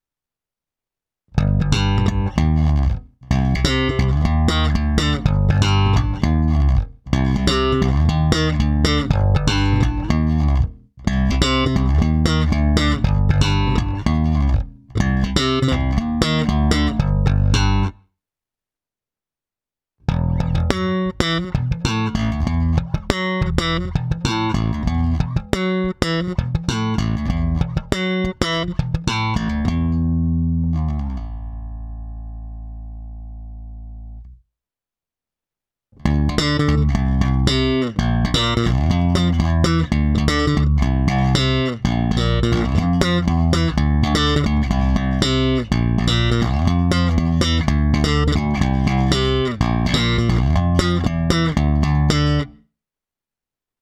Nahrávku jsem prohnal multiefektem Hotone Ampero, ale v něm mám zapnutou prakticky jen drobnou ekvalizaci a hlavně kompresor.
Oba snímače slap - basy +50%, středy +50%, výšky +50%